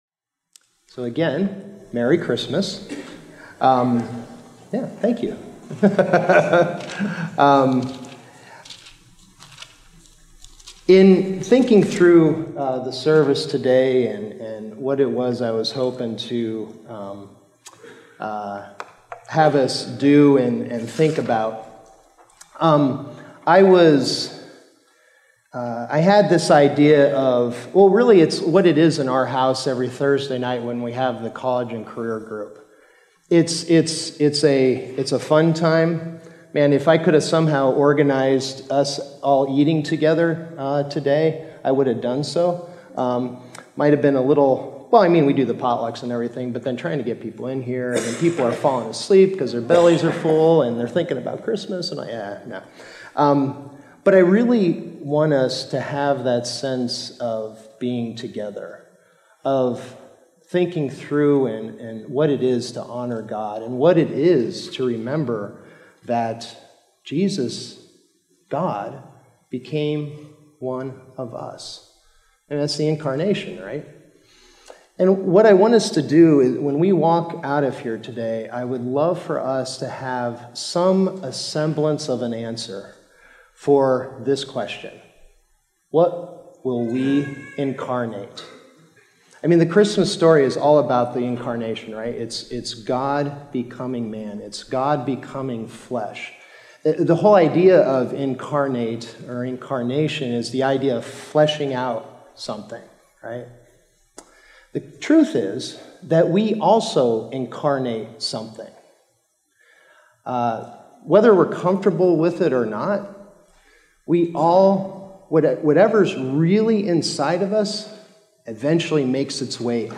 Sermon Detail
December_24th_Sermon_Audio.mp3